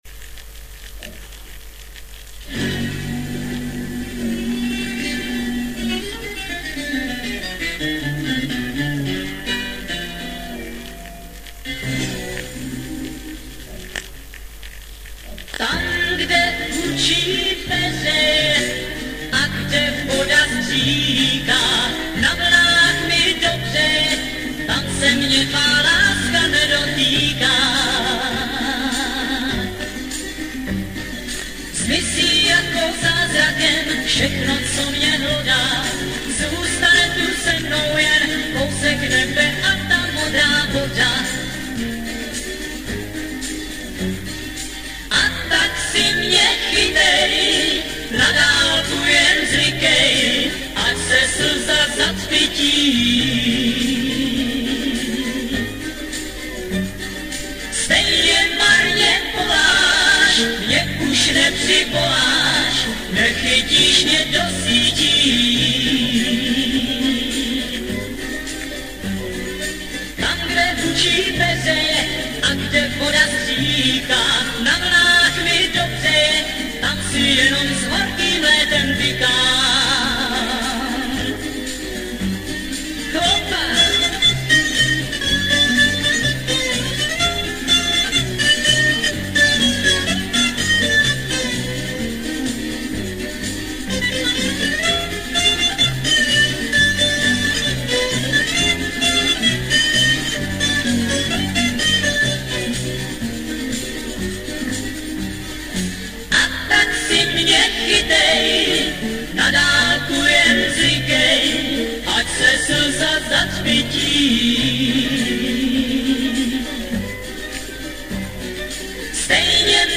Format: Vinyl, 7", 33 ⅓ RPM, EP, Mono
Genre: Rock, Pop
Style: Pop Rock, Schlager